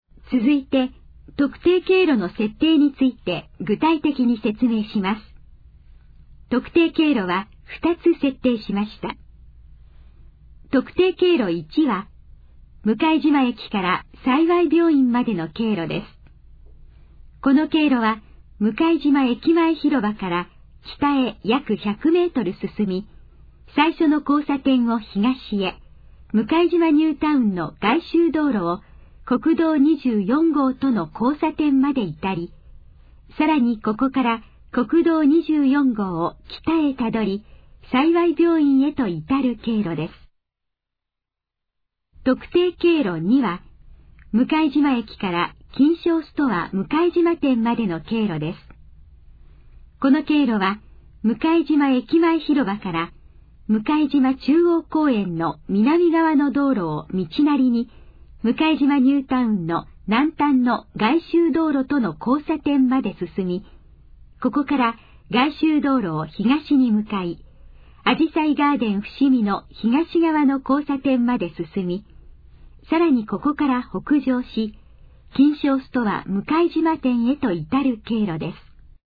以下の項目の要約を音声で読み上げます。
ナレーション再生 約159KB